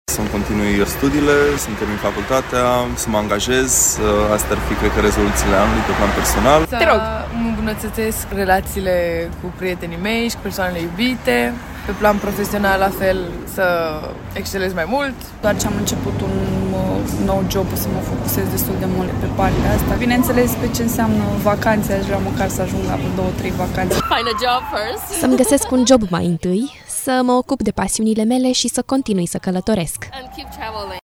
„Să-mi îmbunătățesc relațiile cu prietenii mei și cu persoanele iubite. Pe plan profesional, la fel, să excelez mai mult”, a declarat o tânără
„Să-mi continui studiile, să-mi continui facultatea, să mă angajez. Astea cred că ar fi rezoluțiile anului pe plan personal”, spune un tânăr.